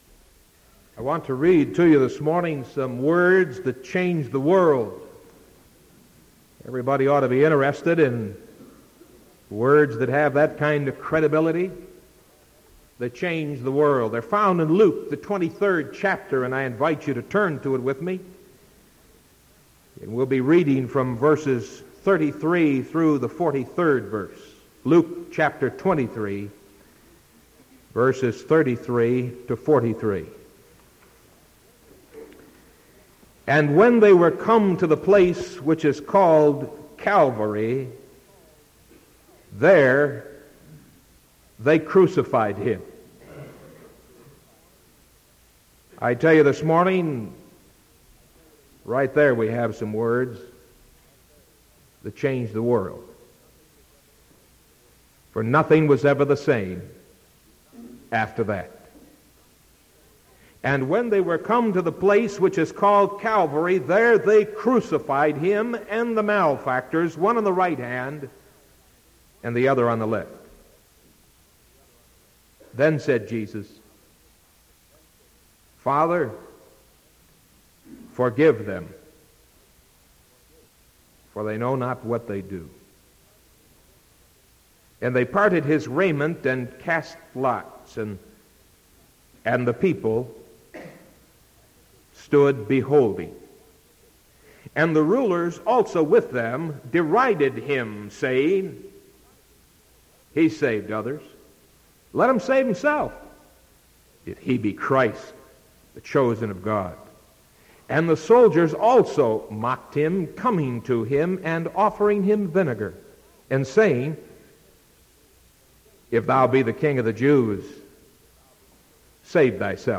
Sermon March 9th 1975 AM